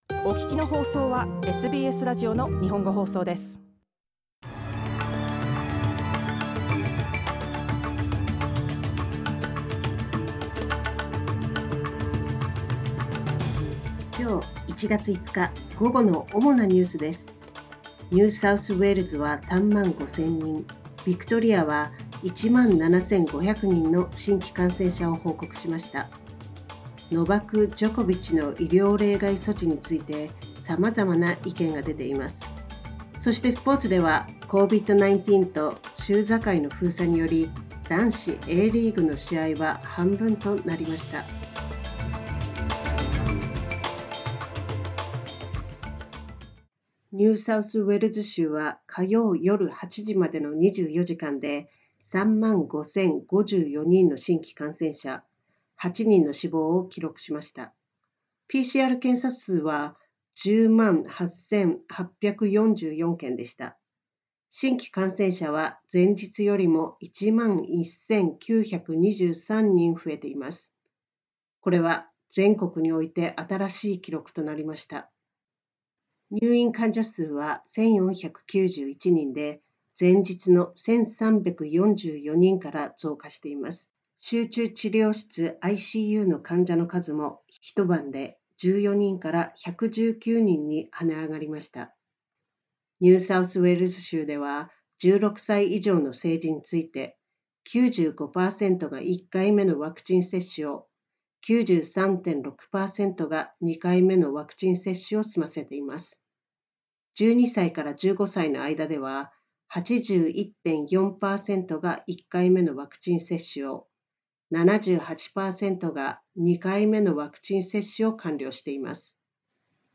１月５日の午後のニュースです
Afternoon news in Japanese, ５ January 2022